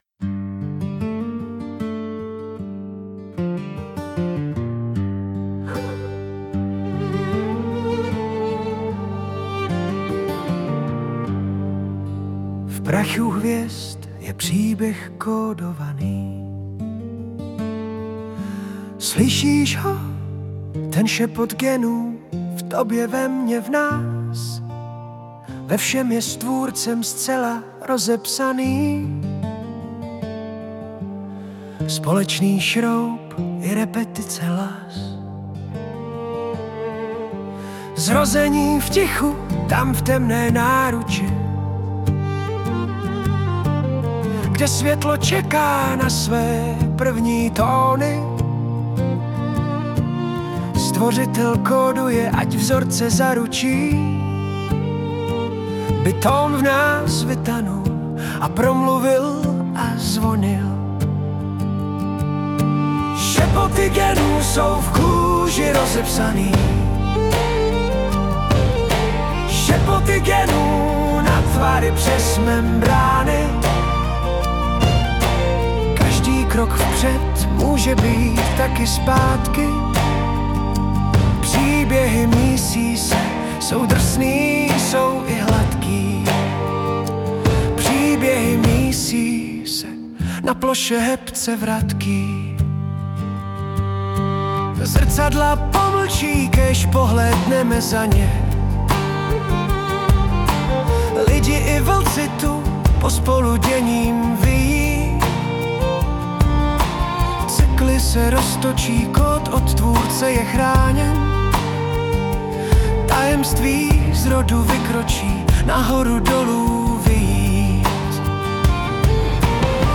ale nakonec se mi nejvíc líbila tahle jemnější